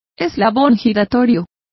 Complete with pronunciation of the translation of swivels.